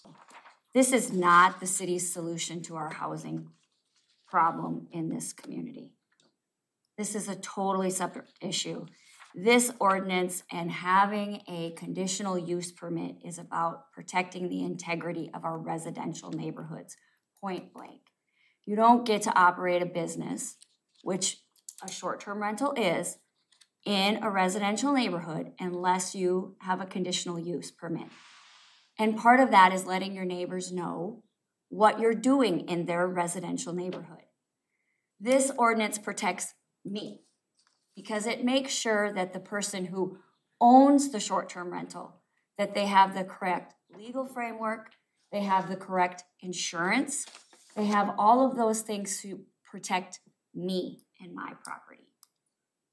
The first reading of an ordinance revision regarding Airbnbs, also called short-term rentals, was approved.  Council member Amy Cerney talked about the ordinance from her perspective.